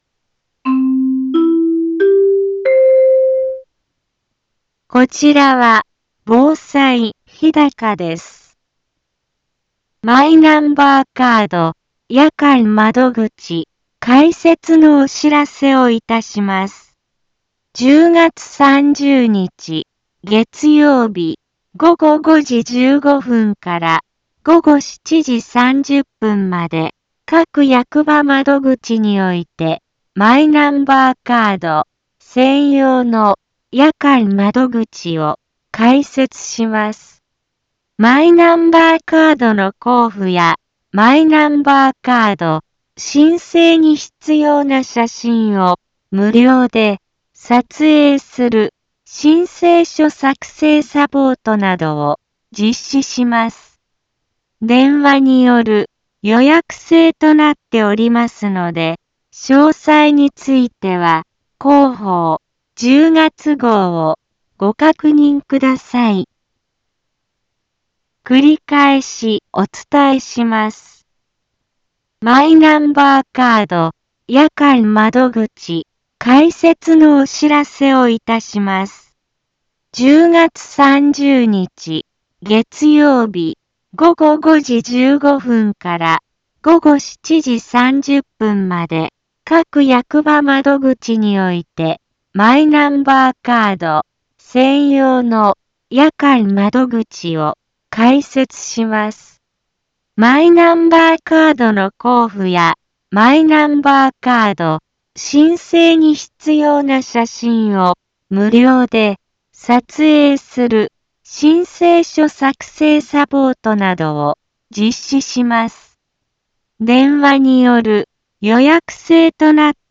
一般放送情報
Back Home 一般放送情報 音声放送 再生 一般放送情報 登録日時：2023-10-24 10:05:00 タイトル：マイナンバーカード夜間窓口のお知らせ インフォメーション：こちらは防災日高です。